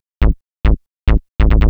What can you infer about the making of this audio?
Techno / Bass / SNTHBASS122_TEKNO_140_A_SC2.wav